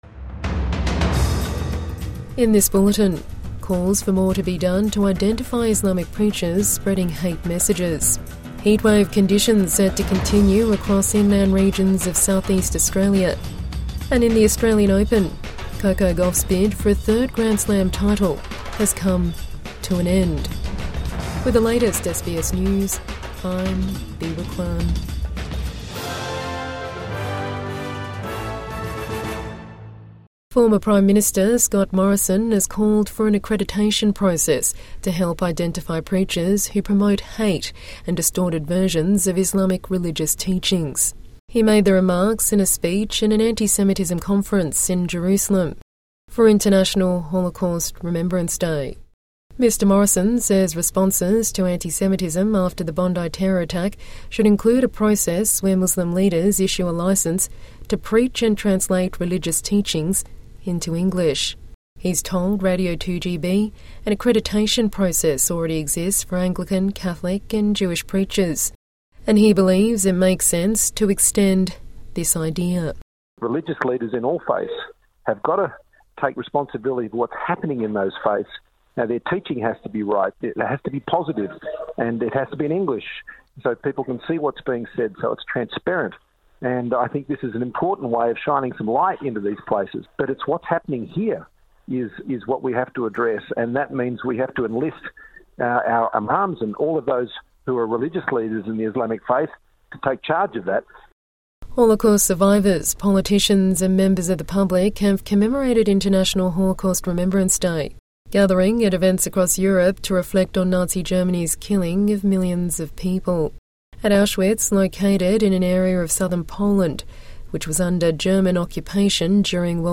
Call for action on Islamic preachers spreading hate messages | Midday News Bulletin 28 January 2026